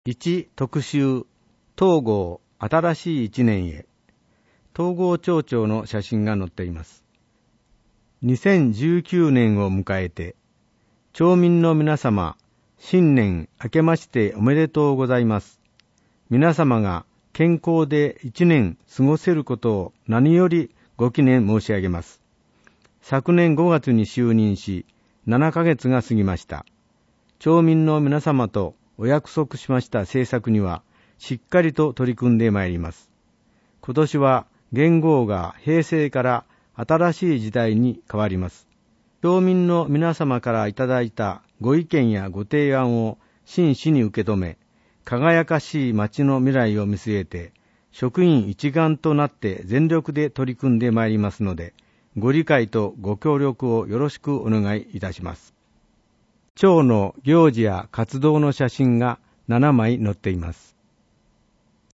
広報とうごう音訳版（2019年1月号）